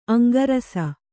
♪ aŋgarasa